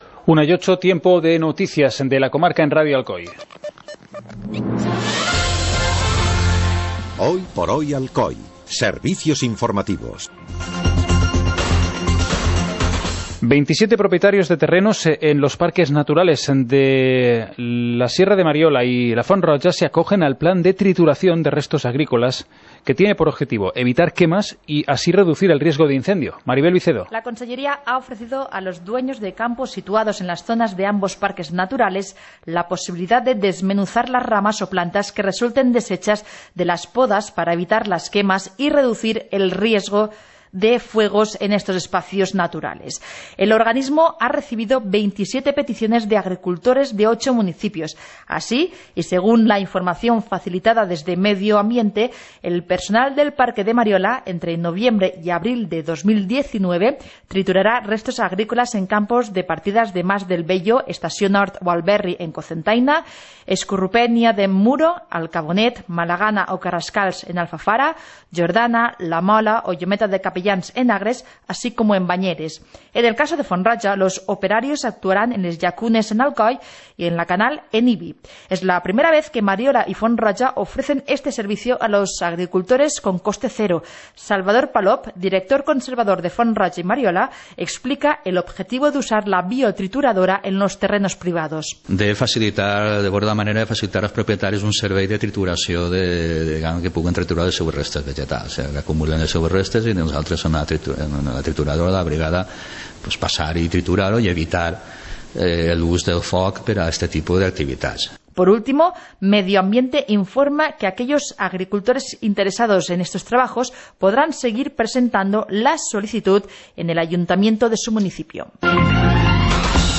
Informativo comarcal - viernes, 31 de agosto de 2018